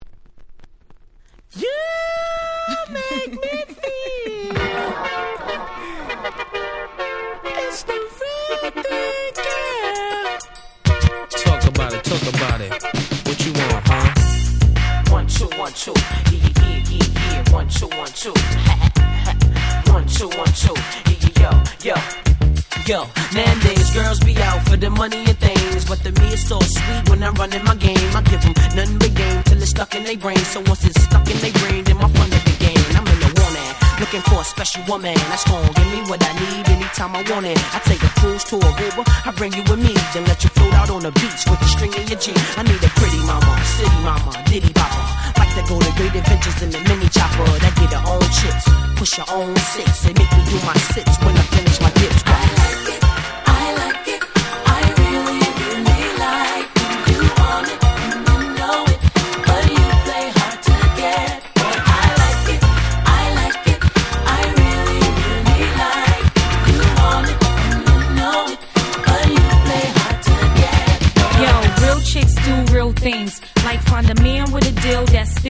99' Big Hit Hip Hop !!!!!
キャッチー系